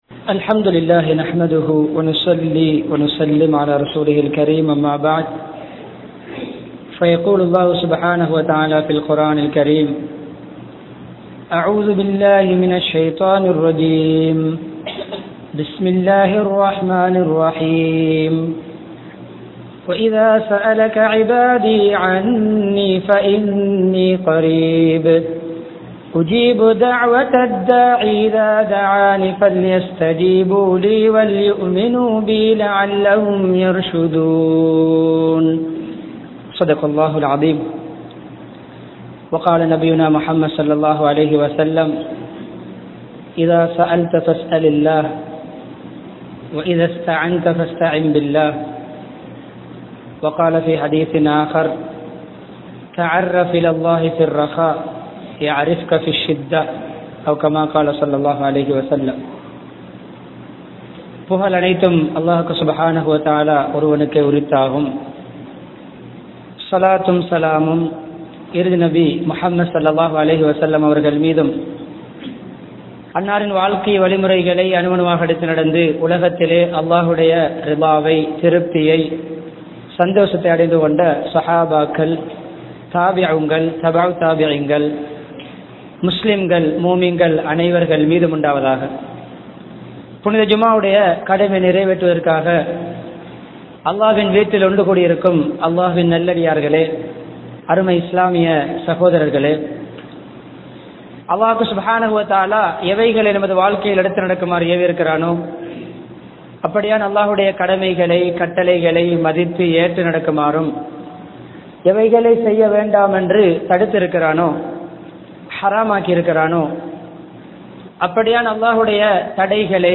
Halalaaha Ulaiungal (ஹலாலாக உழையுங்கள்) | Audio Bayans | All Ceylon Muslim Youth Community | Addalaichenai
Wattala, Mabola, Duwatta Jumua Masjidh